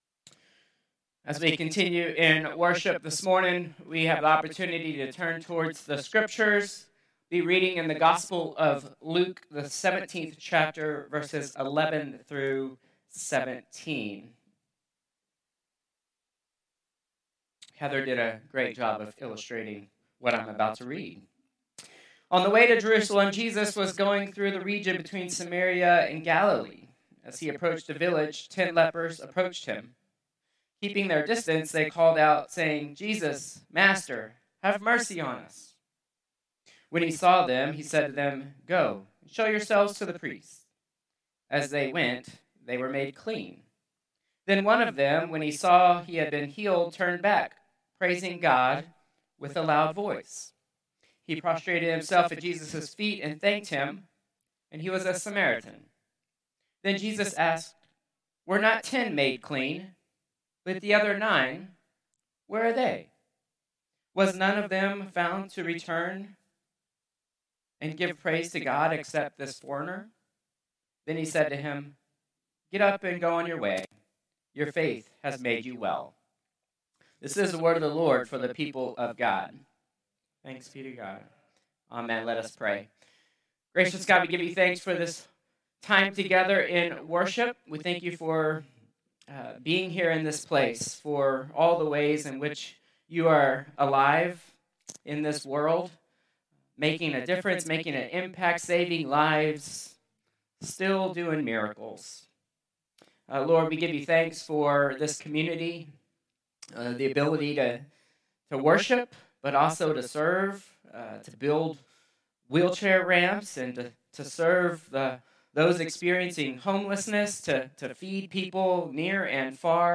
Contemporary Service 10/12/2025